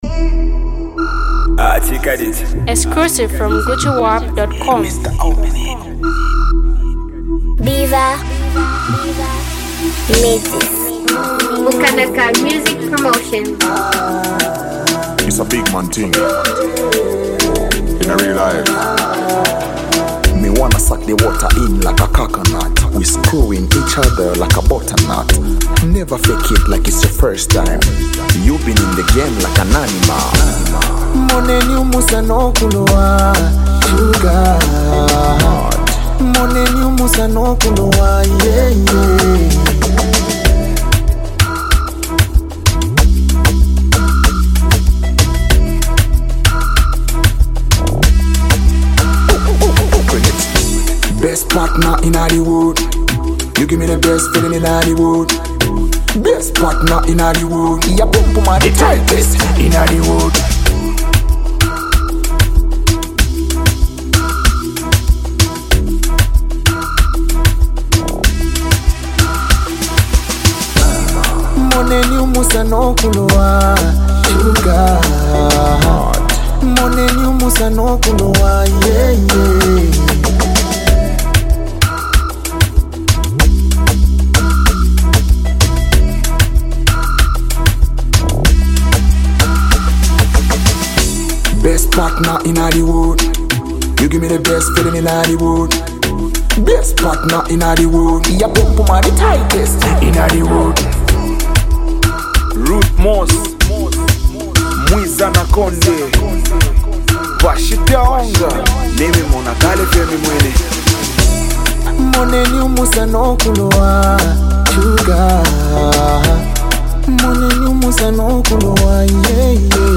soulful voice
this heartfelt love song is a must-listen.